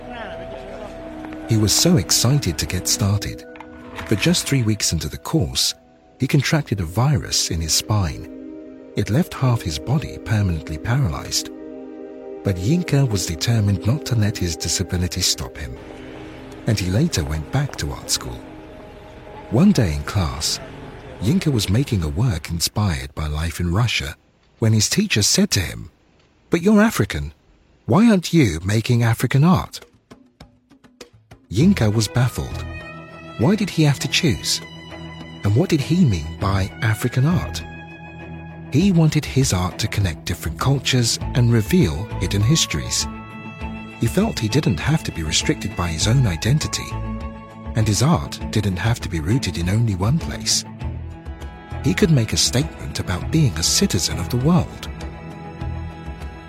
Animation
Sennheiser MKH 416 / Shure SM7B